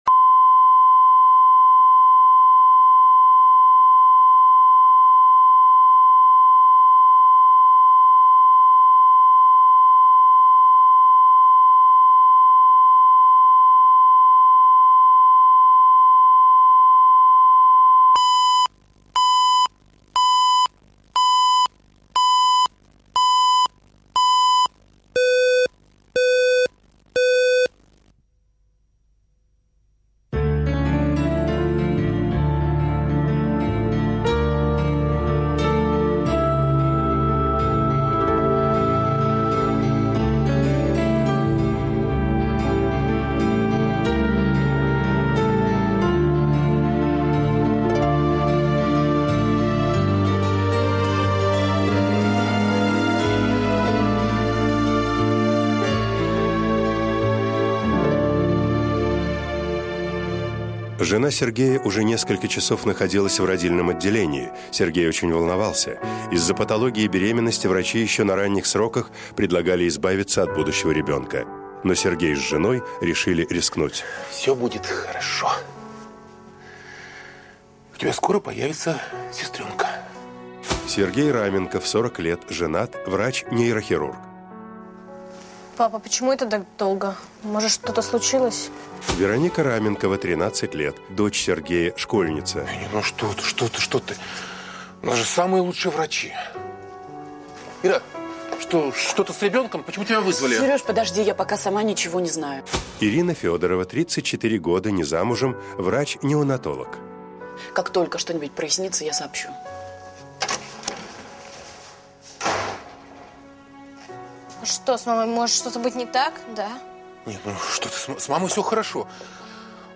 Аудиокнига Мужчины не плачут | Библиотека аудиокниг